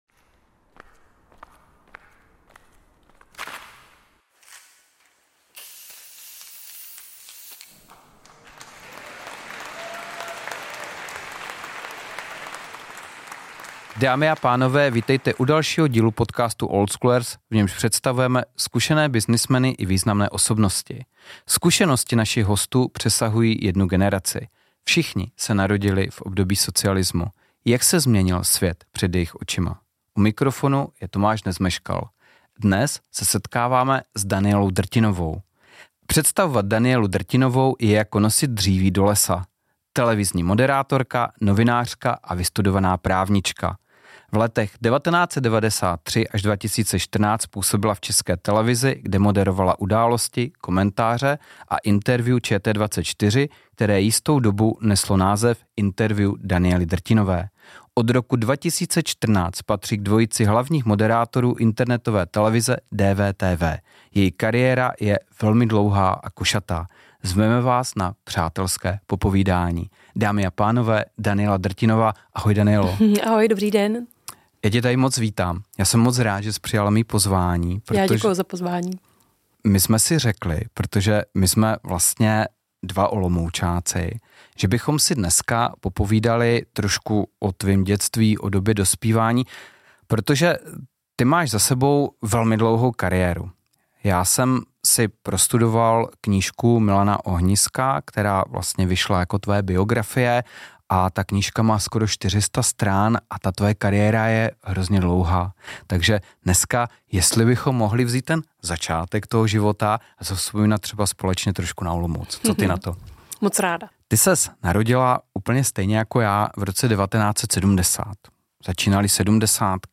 Zveme vás na přátelské popovídání.